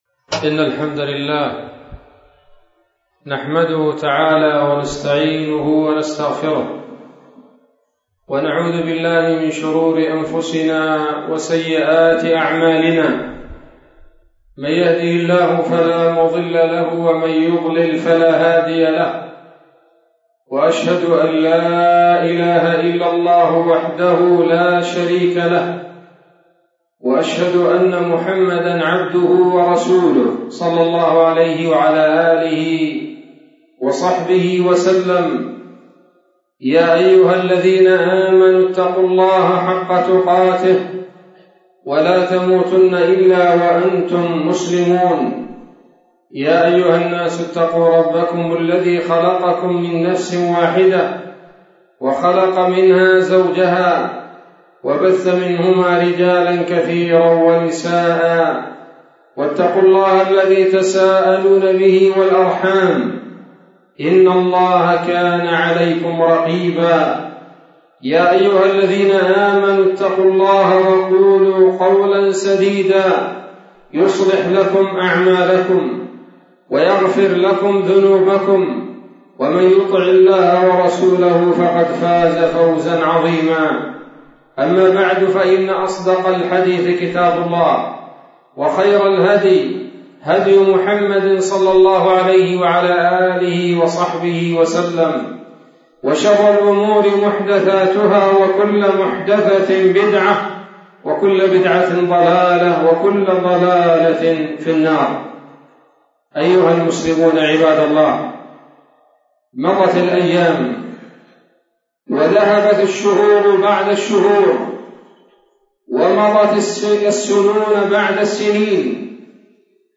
خطبة جمعة بعنوان: (( وداعًا عامنا )) 23 ذو الحجة 1443 هـ، بمسجد الرحمن - حبيل الفتح - حطيب - يافع